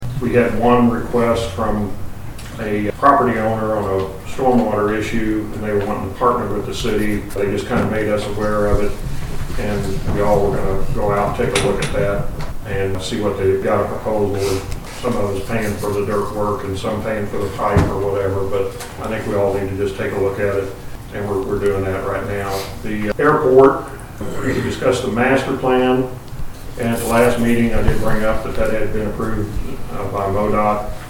Ward III Councilman Dan Brandt gave the Storm Water Tip of the Month during the meeting of the Marshall City Council on Monday, June 7.